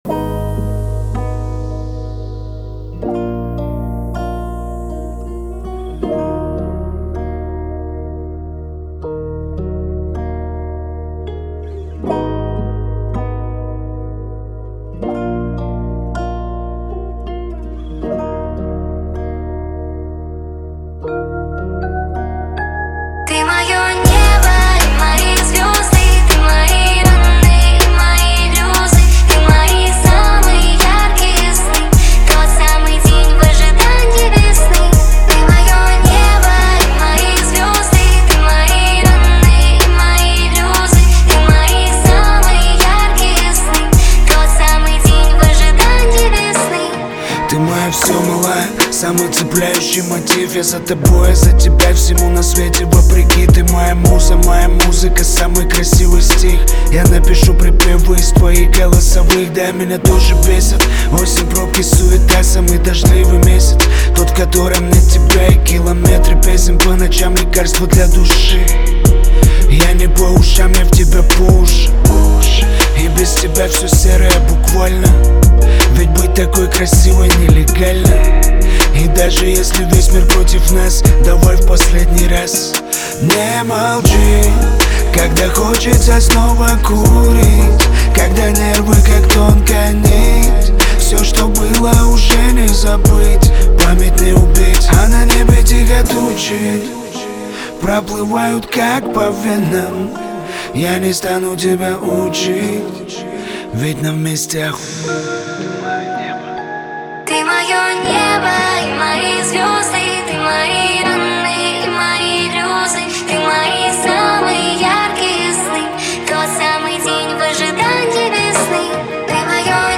Качество: 320 kbps, stereo
Русские поп песни